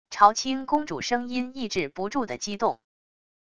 朝倾公主声音抑制不住的激动wav音频